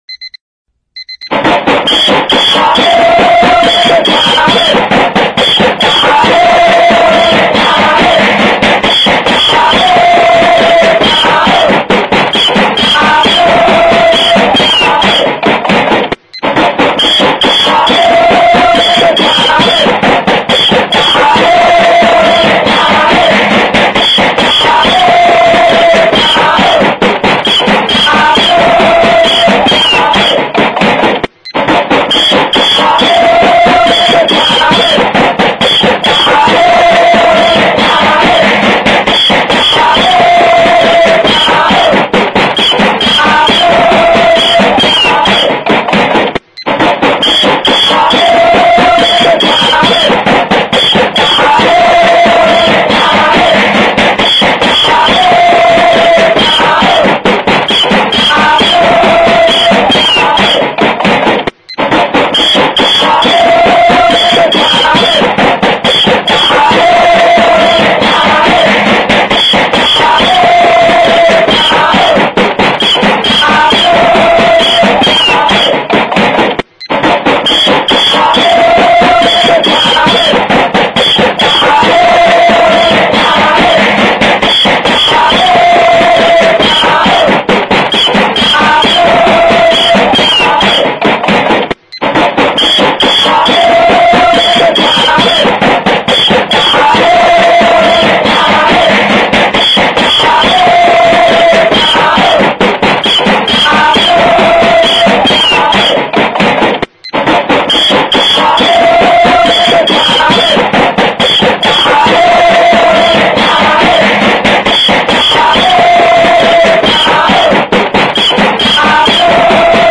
Nada suara alarm sahur berisik
Kategori: Nada dering
nada-suara-alarm-berisik-sahur-id-www_tiengdong_com.mp3